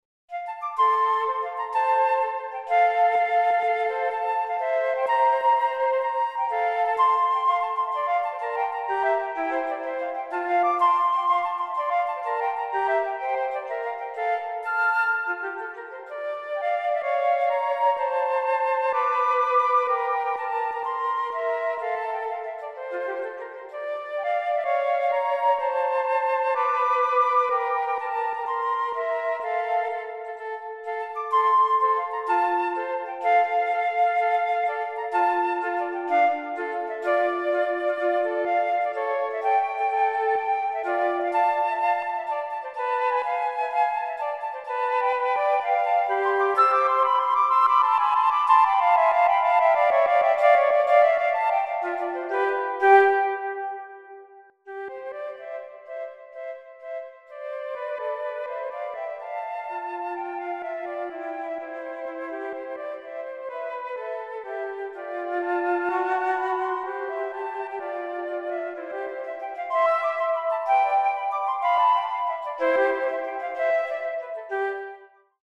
für 2 Flöten
Allegretto Rondo